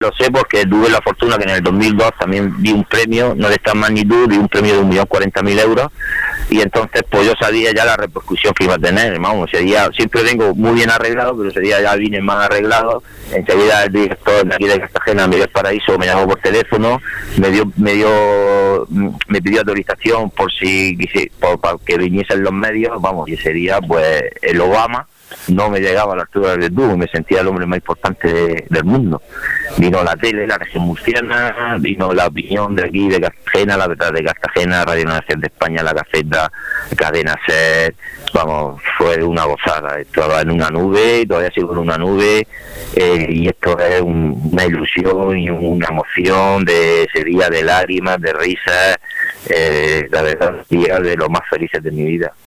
Un hombre emotivo y espontáneo, de voz cantarina.